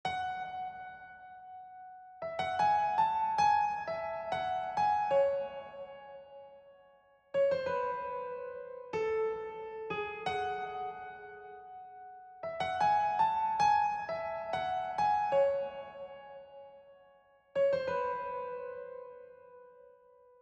SILK-Tutorial-PIANO-TRACK.wav